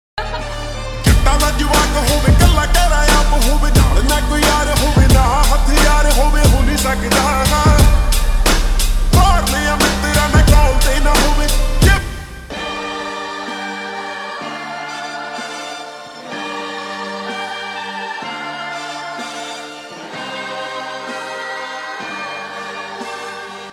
Punjabi Songs
(Slowed + Reverb)